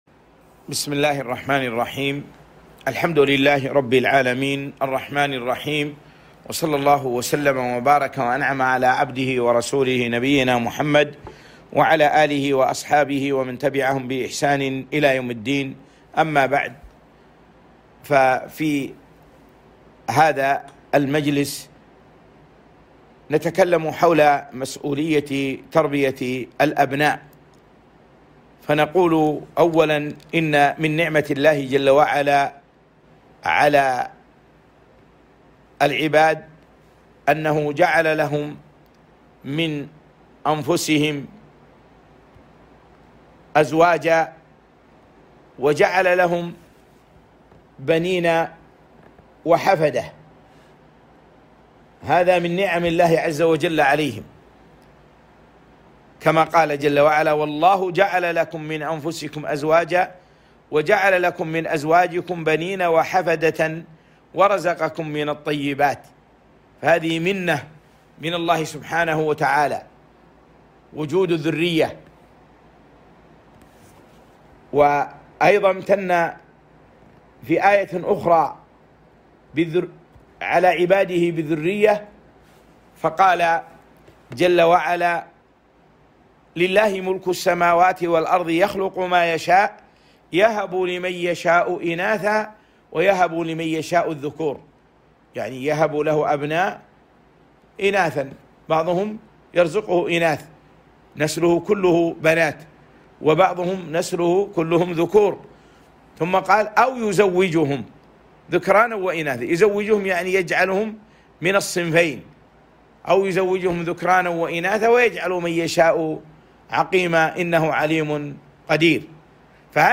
كلمة - مسؤولية تربية الابناء